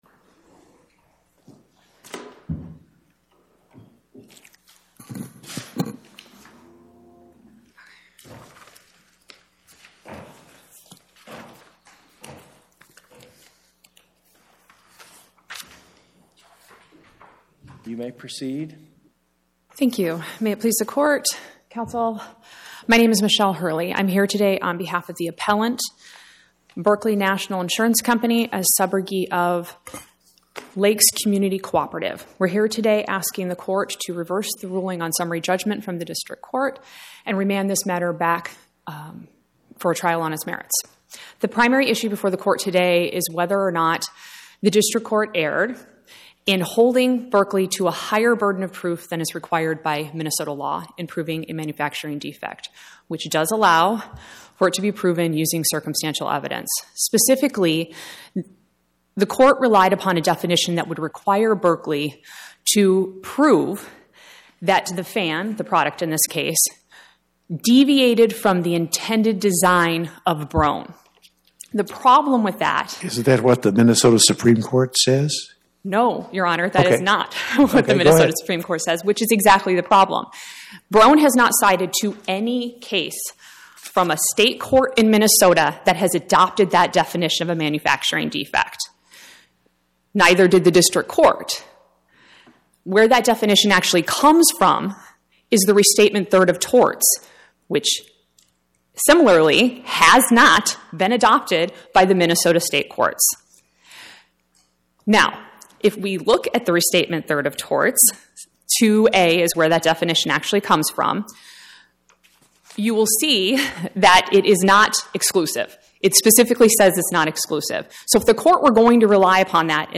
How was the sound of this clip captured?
My Sentiment & Notes 25-1885: Berkley National Insurance Co. vs Broan-Nutone, LLC Podcast: Oral Arguments from the Eighth Circuit U.S. Court of Appeals Published On: Tue Feb 10 2026 Description: Oral argument argued before the Eighth Circuit U.S. Court of Appeals on or about 02/10/2026